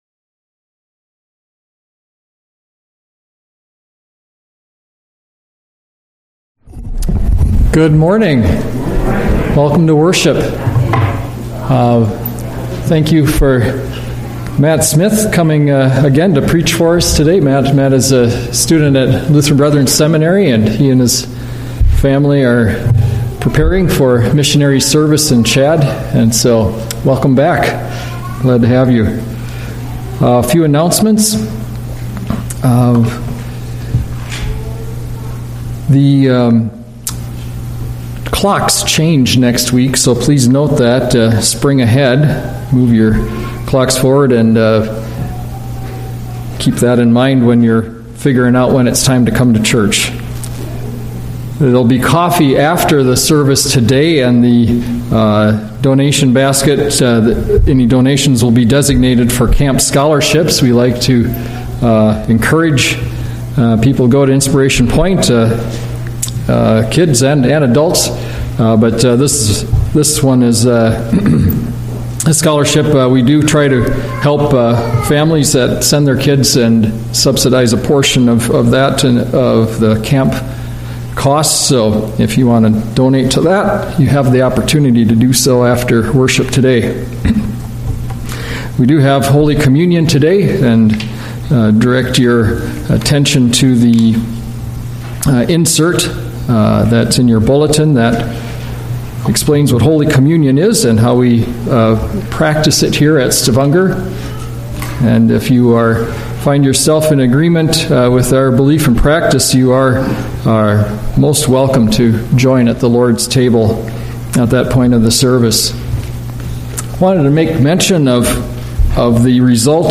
A message from the series "Sunday Worship." First But Not Last - Luke 5:1-11